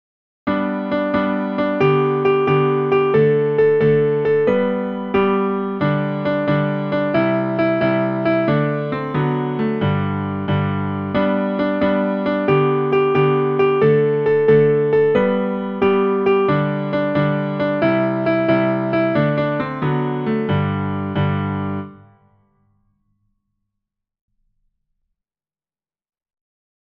Nursery Rhymes:
piano